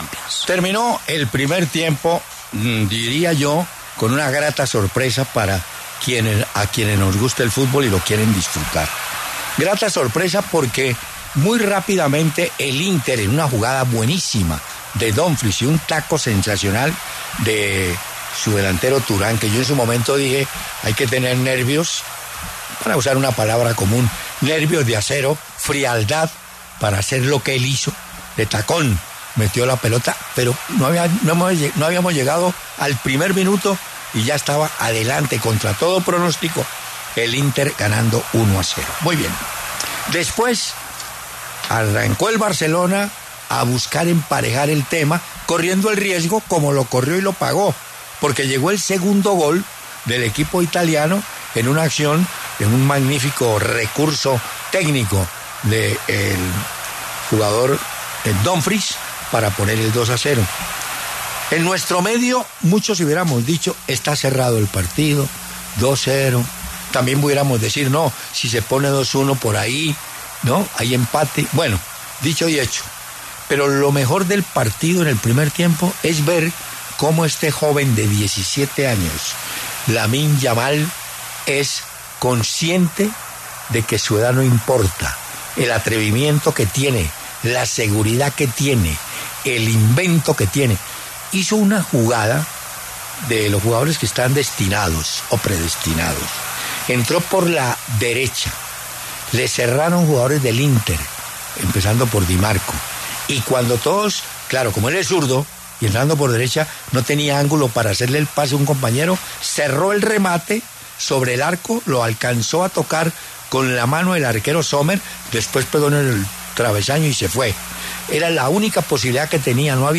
Hernán Peláez, reconocido periodista deportivo, analizó en los micrófonos de W Radio el primer tiempo del compromiso entre Barcelona e Inter de Milán por el duelo de ida de las semifinales de Champions League.